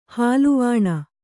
♪ hāluvāṇa